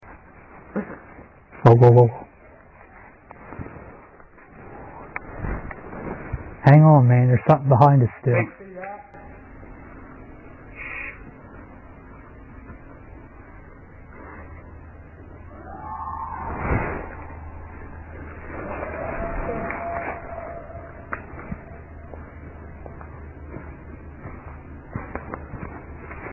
Category: Animals/Nature   Right: Personal
Tags: Wildlife audio recordings Unknow Wildlife Souns